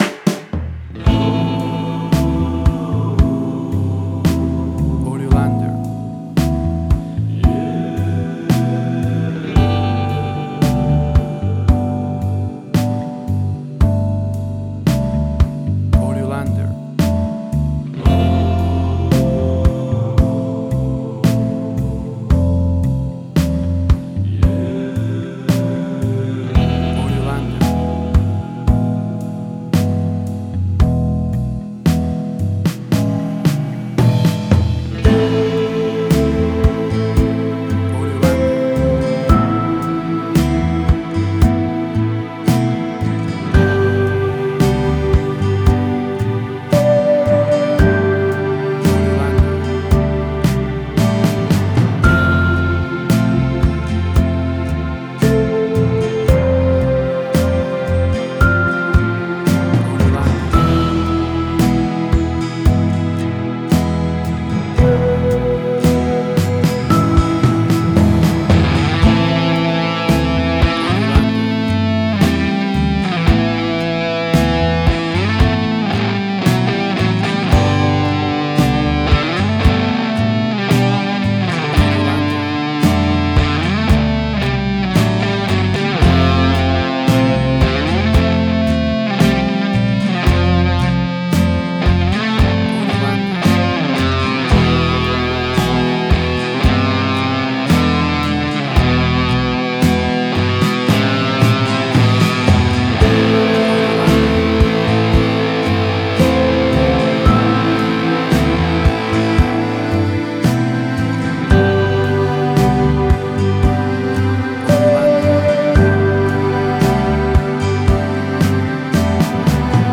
Retro-60s sounds.
Tempo (BPM): 113